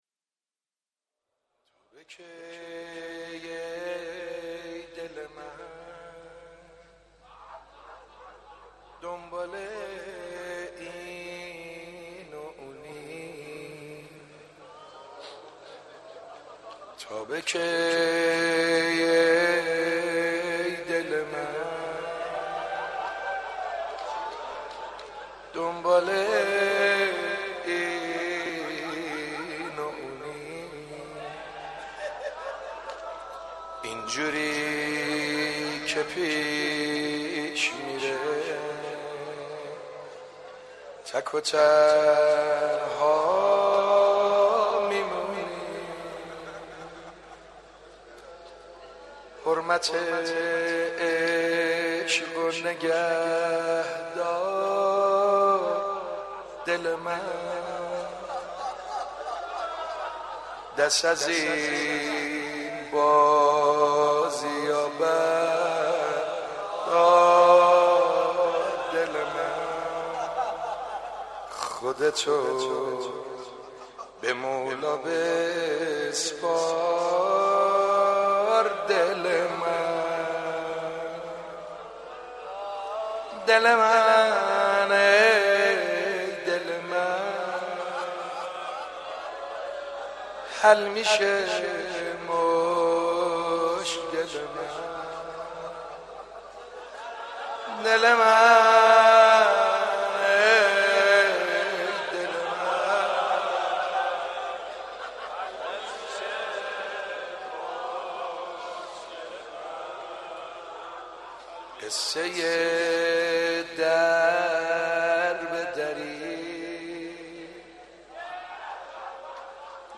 حاج سعید حدادیان/مناجات با امام زمان(عج)